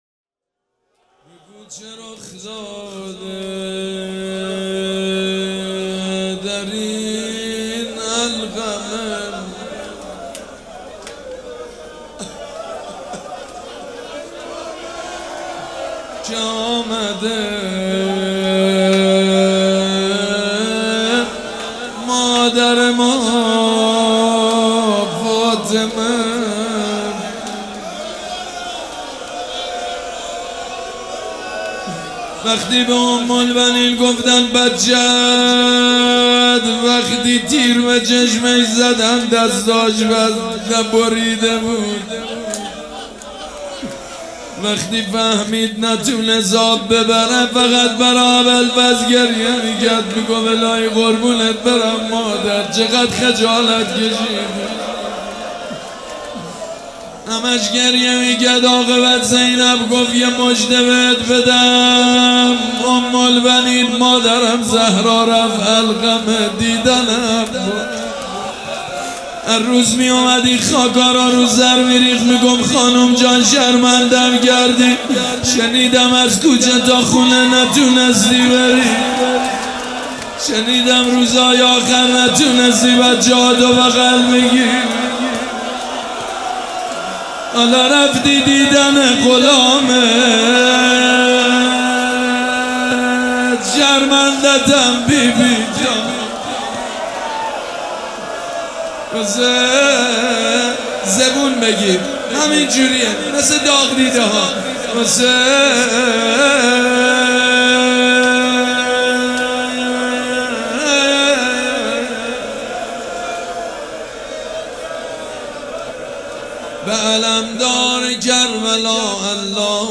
روضه
مداح
مراسم عزاداری شب چهارم